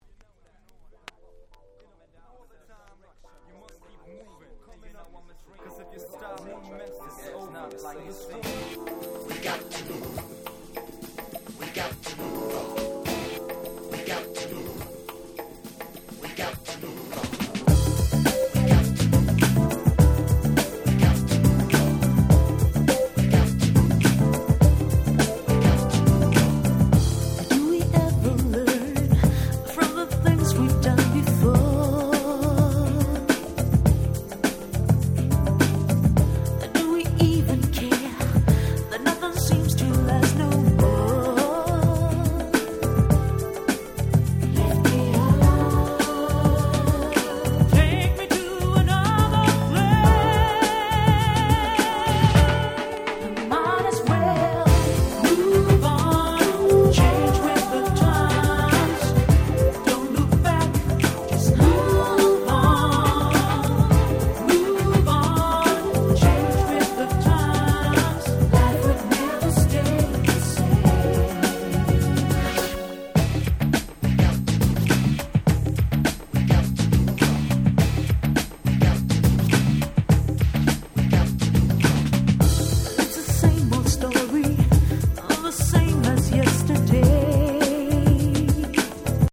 92' Super Nice UK Soul !!
流れる様な爽やかさに軽快なリズム、完全に100点満点！！